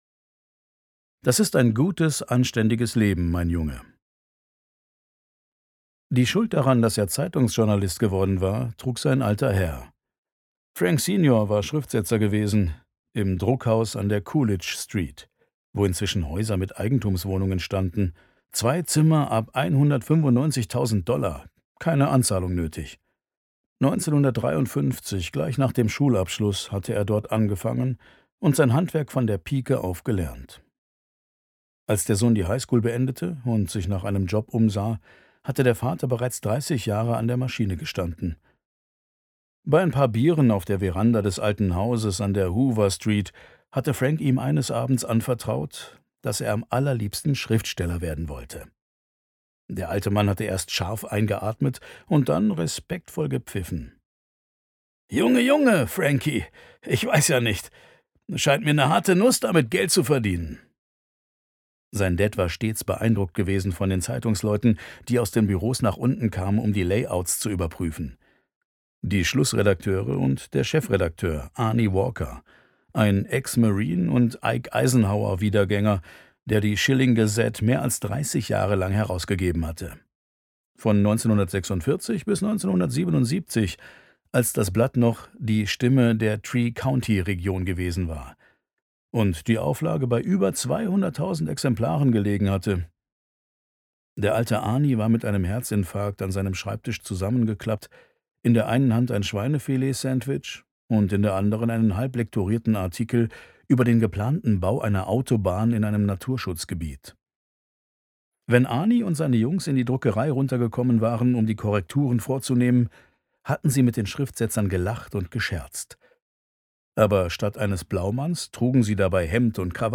2021 | Originalfassung, ungekürzt